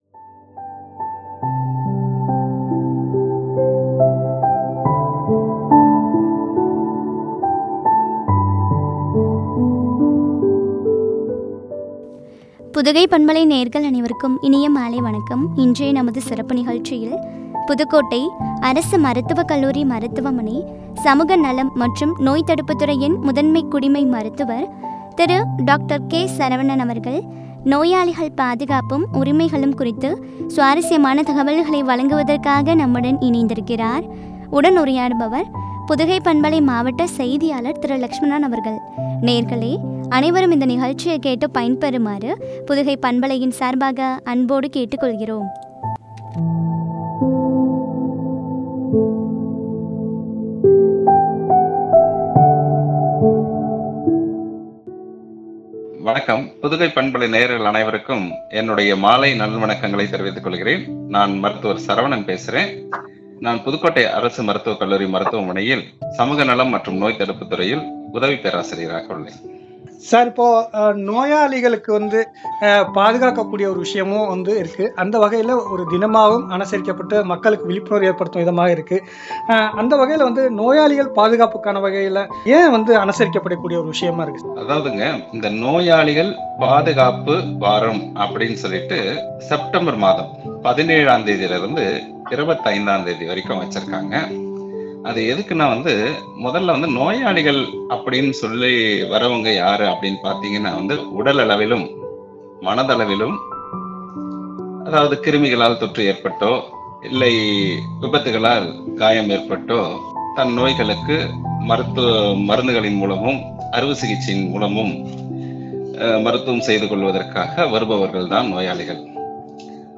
உரிமைகளும் குறித்து வழங்கிய உரையாடல்.